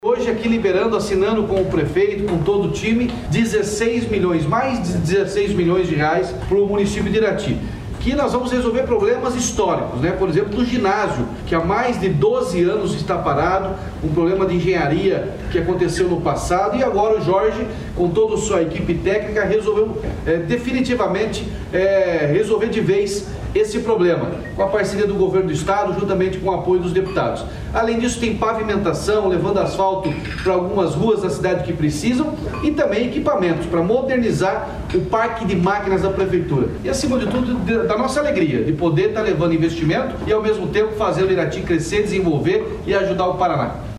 Sonora do governador Ratinho Junior sobre o repasse de R$ 16,5 milhões para pavimentação e conclusão de ginásio em Irati